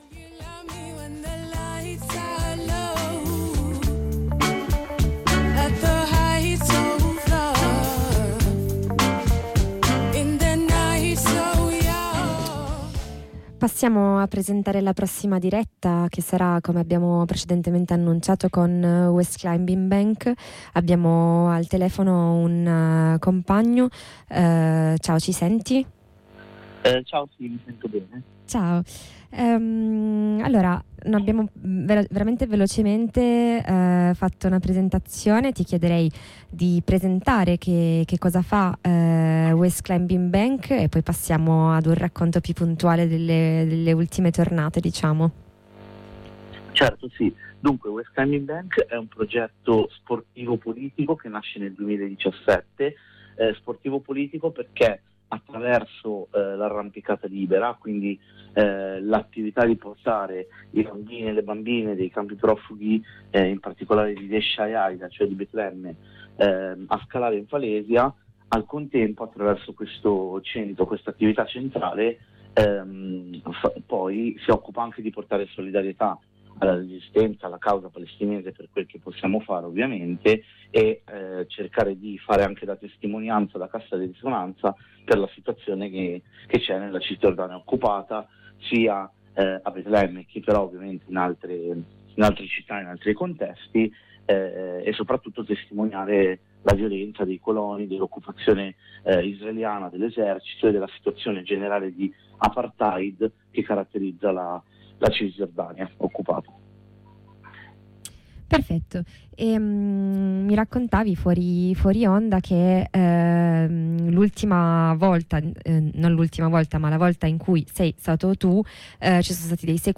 Abbiamo sentito al telefono dell’informazione di radio blackout un compagno che è da poco tornato in Italia dalla Palestina, dove -ci racconta- al suo gruppo sono state sequestrate le attrezzature da arrampicata: